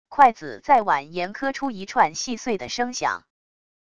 筷子在碗沿磕出一串细碎的声响wav音频